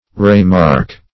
Re-mark \Re-mark"\ (r?-m?rk"), v. t. [Pref. re- + mark.]